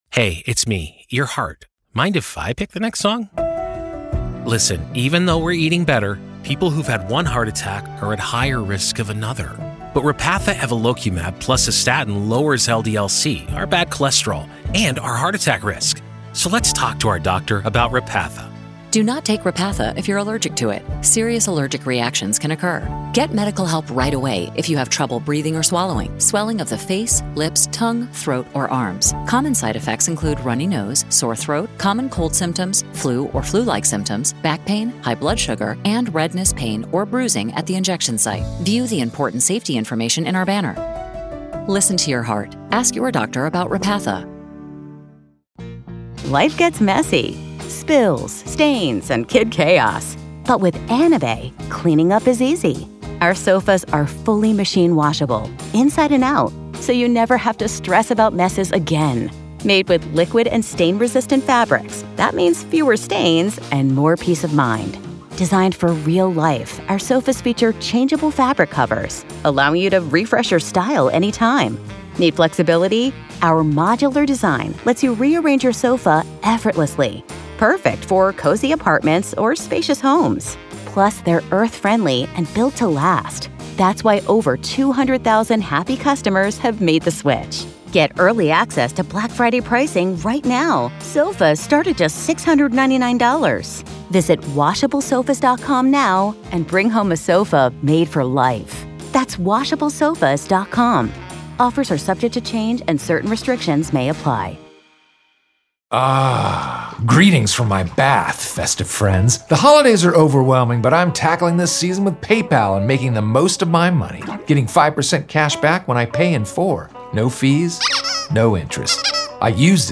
Each day’s proceedings bring new testimony, evidence, and revelations about what happened inside Richneck Elementary School on January 6, 2023 — and the administrative failures that followed. You’ll hear unfiltered courtroom audio, direct from the trial
Hidden Killers brings you the voices, the arguments, and the raw sound of justice in progress — as a jury decides whether silence and inaction inside a public school can rise to the level of legal accountability.